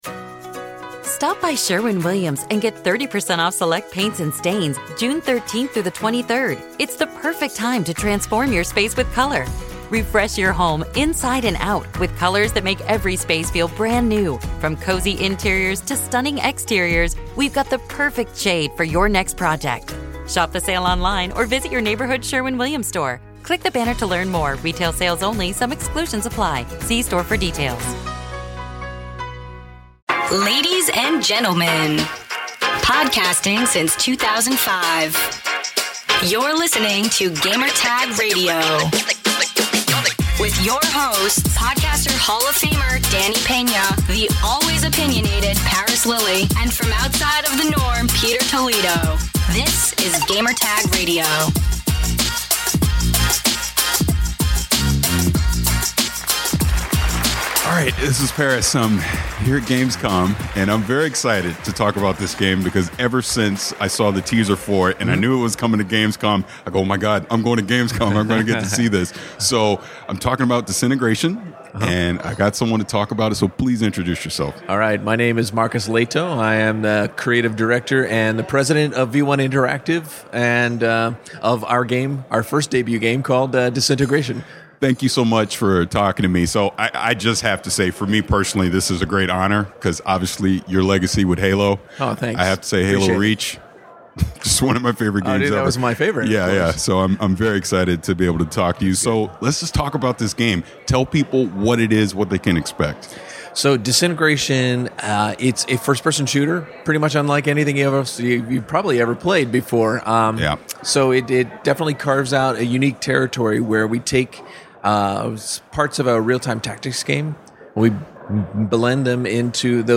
Gamescom 2019